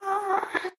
moan2.mp3